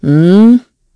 Isolet-Vox-Deny1_kr.wav